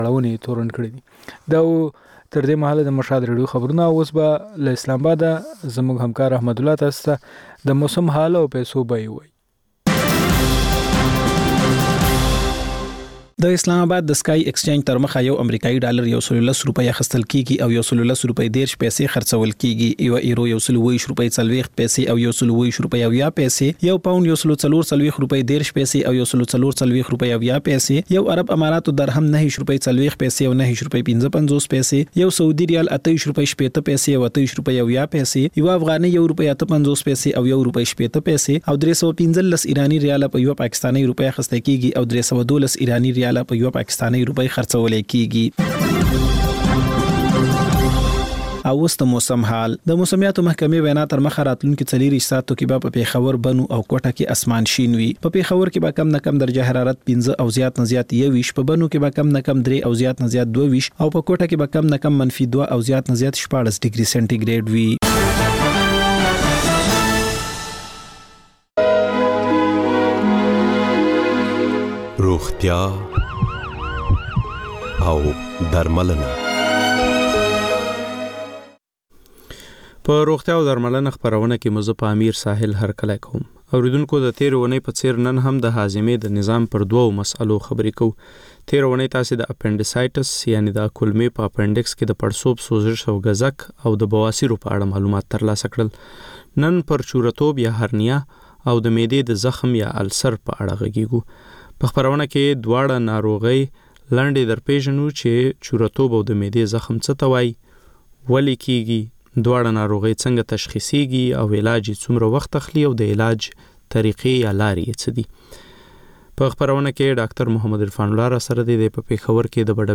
په روغتیا او درملنه خپرونه کې یو ډاکتر د یوې ځانګړې ناروغۍ په اړه د خلکو پوښتنو ته د ټیلي فون له لارې ځواب وايي.